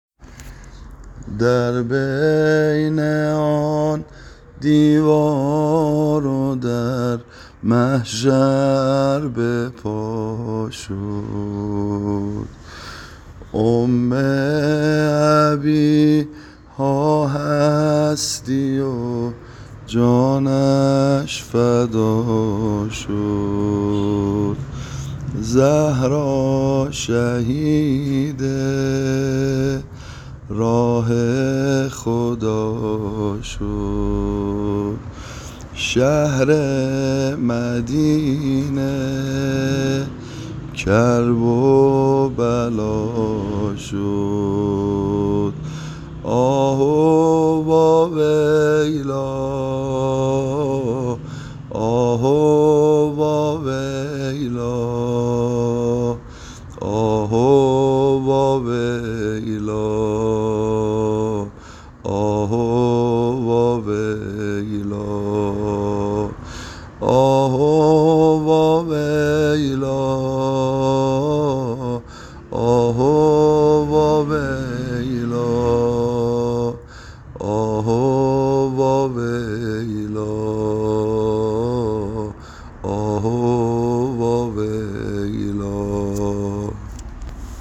متن شعر نوحه مسجدی ایام فاطمیه 1403 با موضوع زبانحال حضرت زهرا سلام الله علیها با بلال حبشی -(یک بارِ دیگر ای بِلال ای یار طاها)
عنوان : به این سبک خوانده میشود